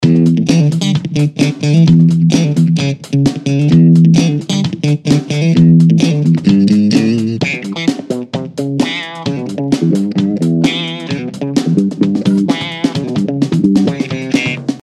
Hier bewege ich Bias vom Minimum zum Maximum und wieder zurück:
Ich kann beim besten Willen keine relevante Klangveränderung feststellen (übrigens auch zuvor mit anderem Audiomaterial und anderen Parameterkonfigurationen nicht).